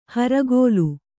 ♪ haragōlu